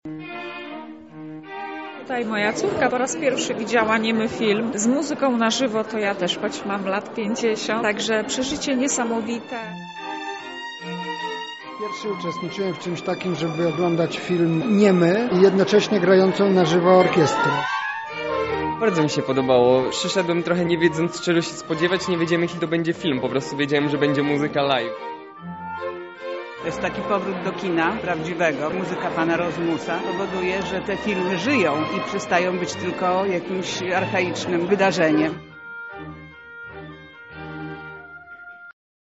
Widzowie podzielili się z nami swoimi wrażeniami po seansie.